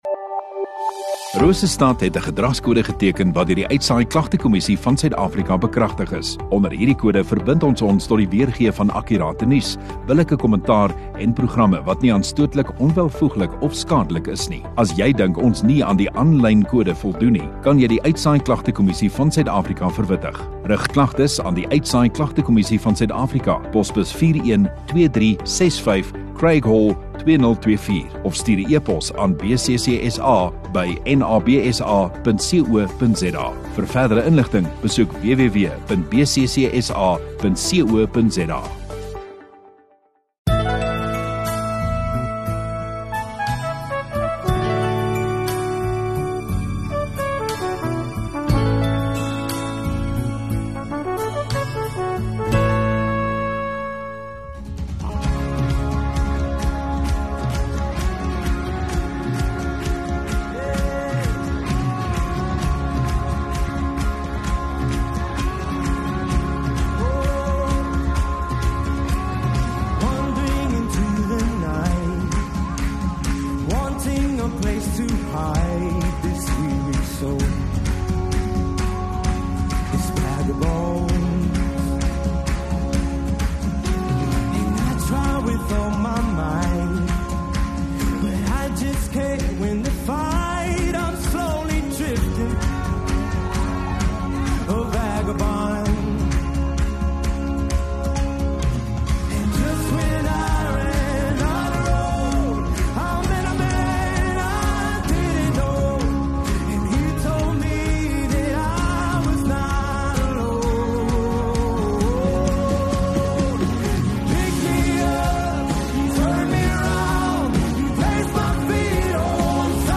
9 Feb Sondagaand Erediens